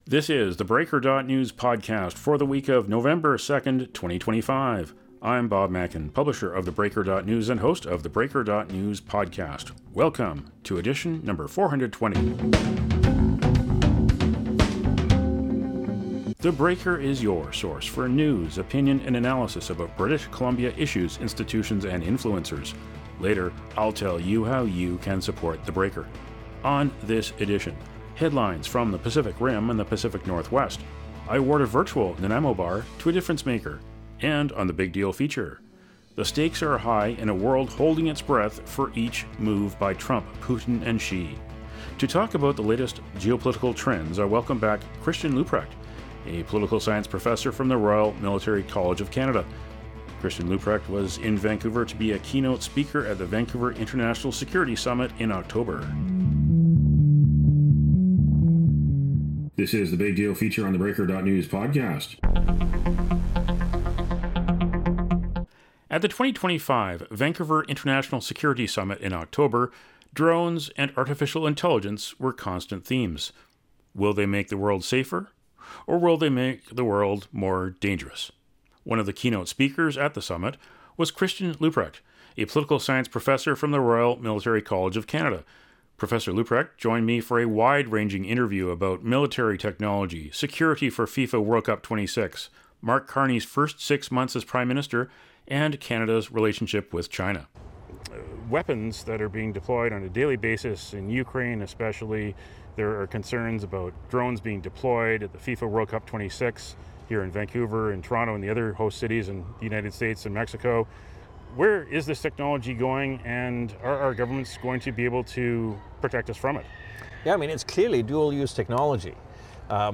In a wide-ranging interview